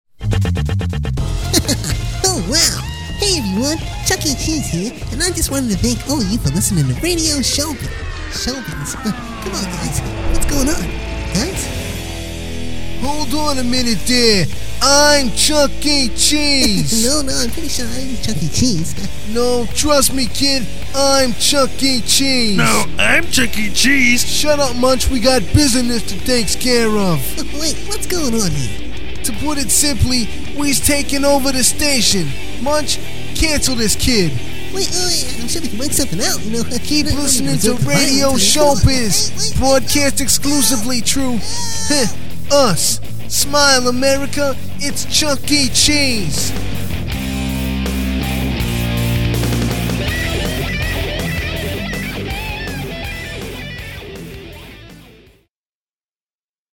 You can hear the promo bumper by clicking here .
fools_2006-bumper.mp3